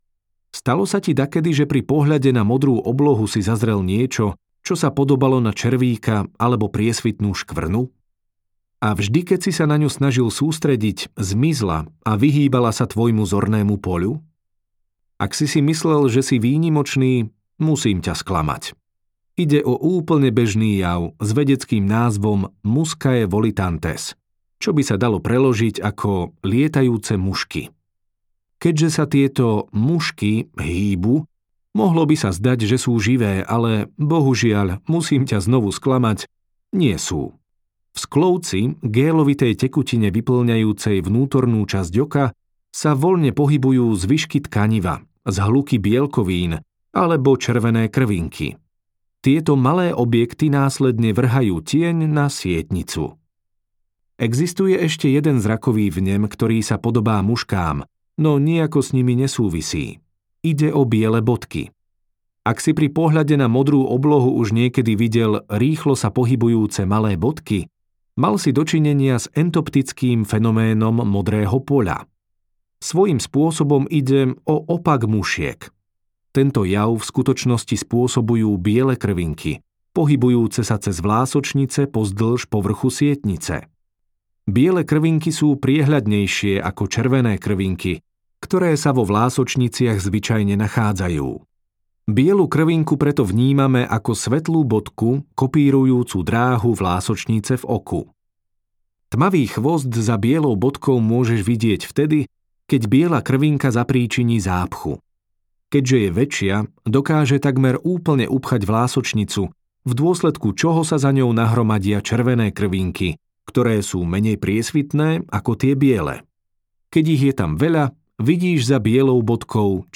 Vedecké okienko audiokniha
Ukázka z knihy